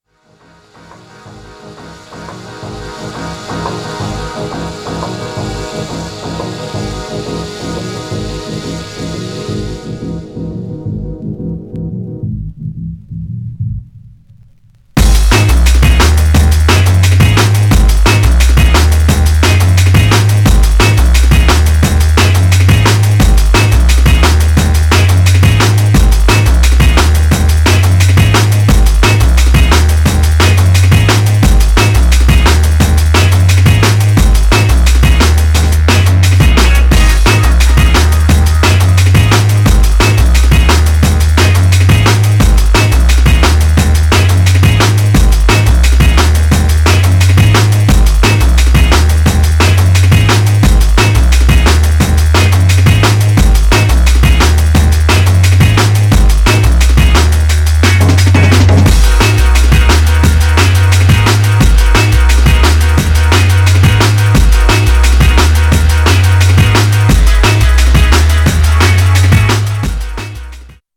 Styl: Drum'n'bass, Breaks/Breakbeat